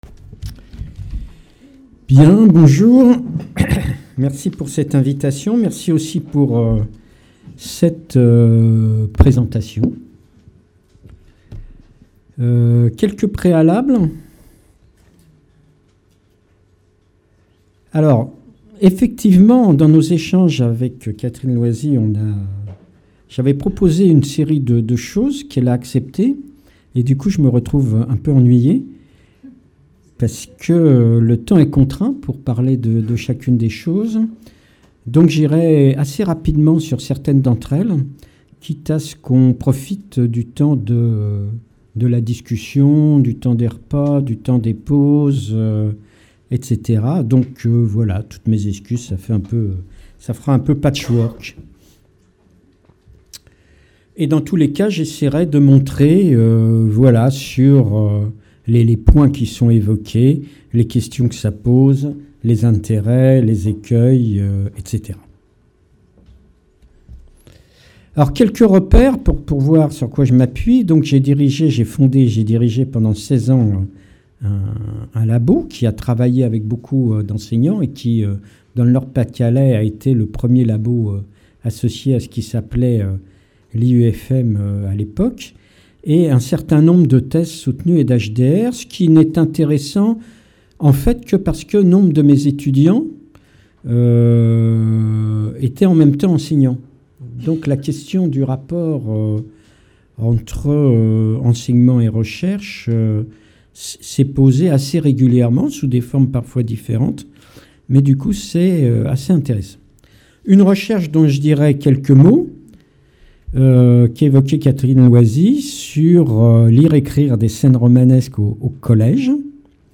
La conférence présentera une réflexion sur une gamme de pratiques articulant d’une manière ou d’une autre l’espace des pratiques et celui des recherches : l’accompagnement de doctorants effectuant une thèse sur les questions d’enseignement et /ou d’apprentissages ; une recherche menée avec des enseignants sur l’articulation entre lecture et écriture à partir de l’écriture ; une recherche sur le groupe solaire Concorde de Mons en Baroeul pratiquant la pédagogie Freinet ; d’autres recherche en cours sur des écoles ou des équipe d’enseignants réunis hors institution. Elle s’intéressera aux questions posées par ces recherches, et précisera leurs intérêts, les écueils rencontrés ainsi que des pistes éventuelles pour les surmonter.